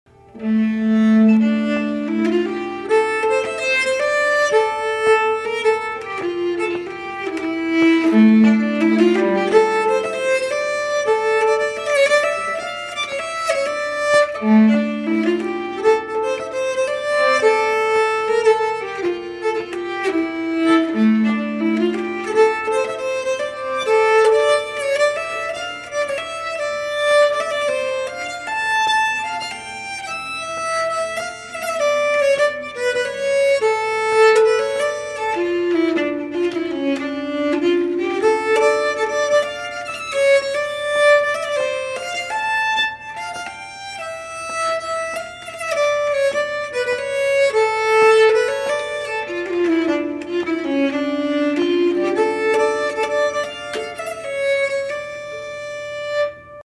Hambo - 7 | Blue Rose
(slow)